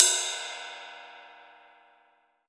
ROCK RIDE.WAV